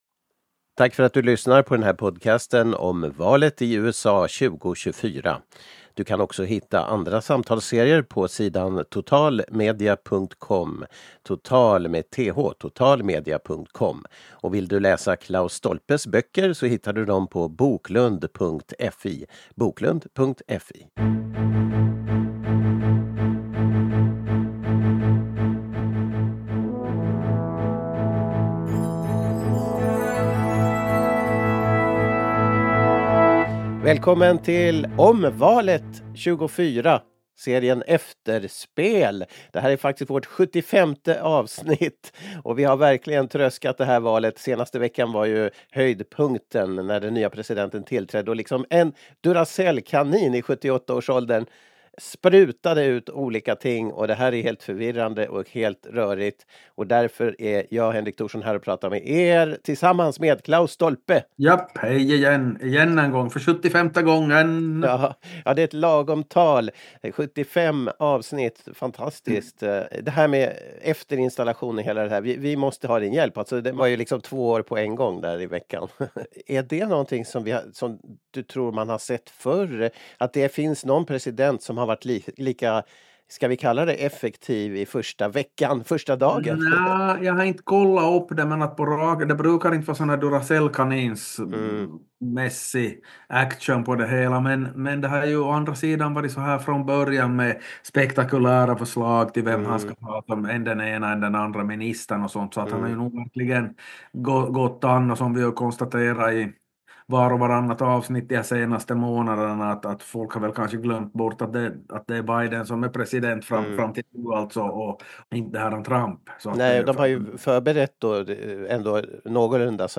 i samtal